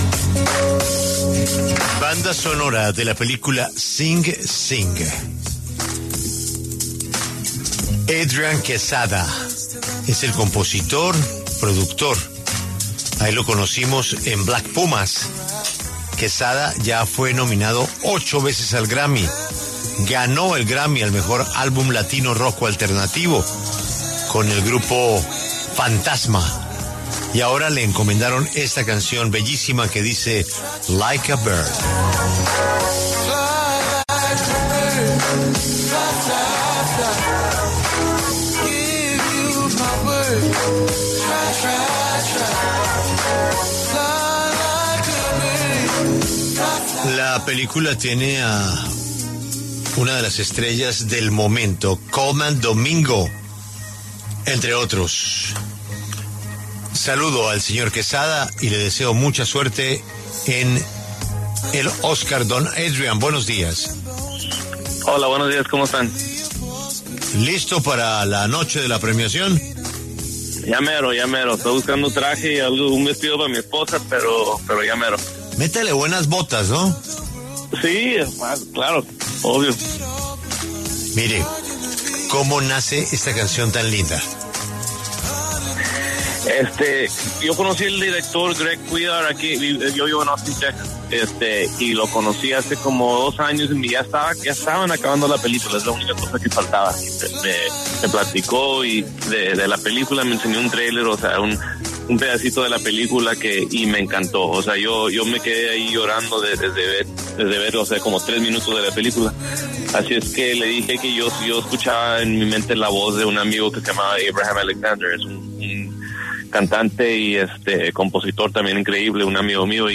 Adrián Quesada, nominado al Oscar a Mejor Canción Original por ‘Like a Bird’, de la película ‘Sing Sing’, conversó con La W sobre su acompañamiento musical a esta producción.